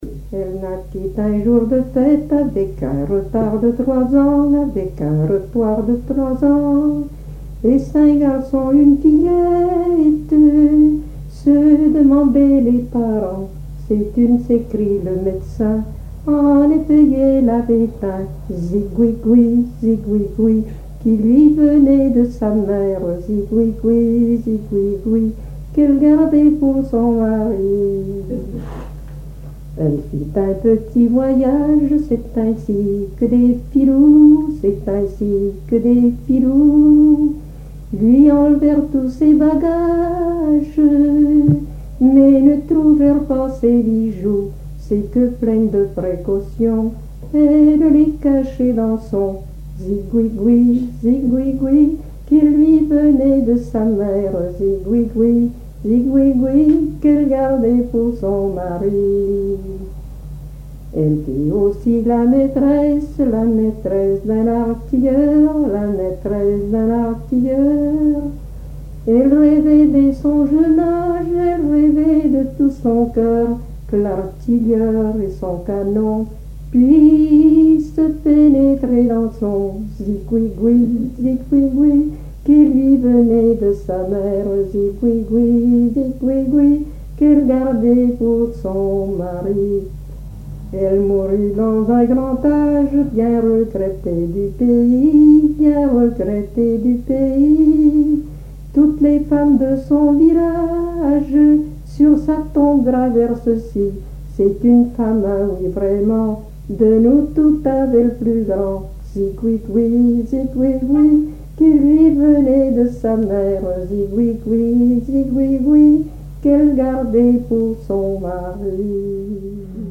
Mémoires et Patrimoines vivants - RaddO est une base de données d'archives iconographiques et sonores.
Saint-Jean-de-Monts
Genre dialogue
Pièce musicale inédite